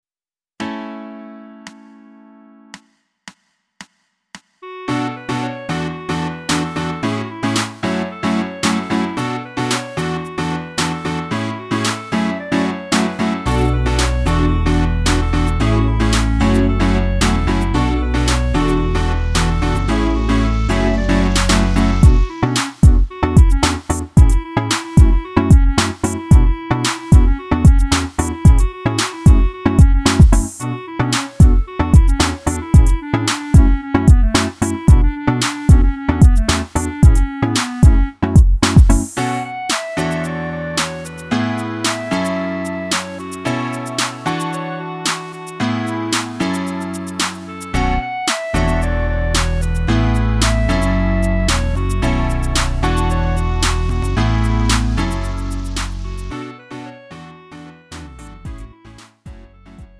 음정 -1키 3:51
장르 가요 구분 Lite MR